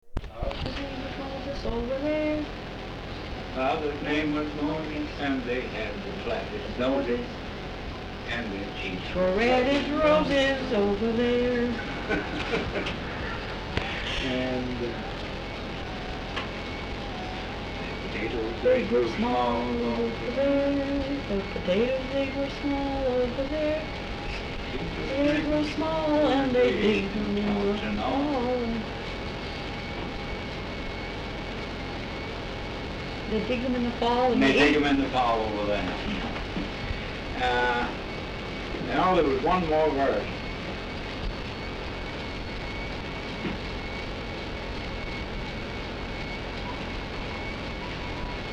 Folk songs, English--Vermont (LCSH)
sound tape reel (analog)
Location Londonderry, Vermont